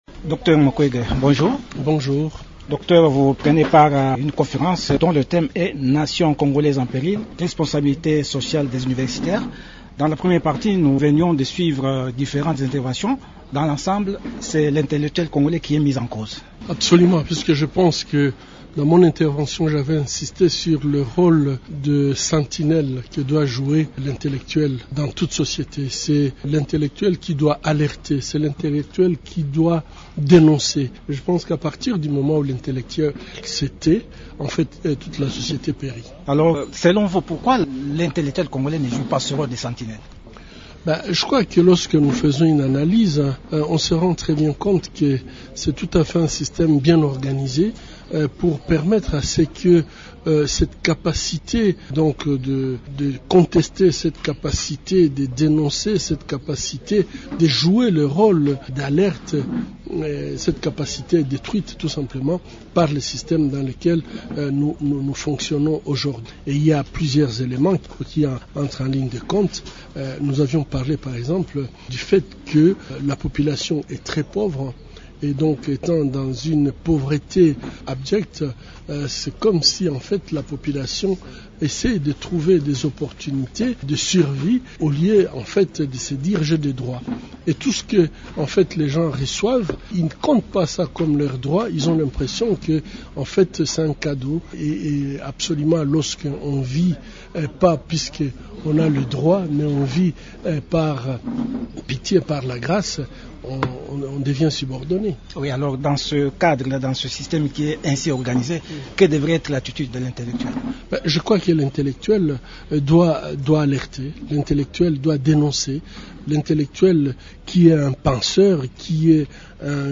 Il a accordé cette interview à l’occasion fait cette déclaration à la conférence des intellectuels congolais organisée à Kinshasa par l’Institut pour la démocratie, la gouvernance, la paix et le développement en Afrique, du 28 au 29 août sous le thème : «Nation congolaise en péril : responsabilité sociale des universitaires».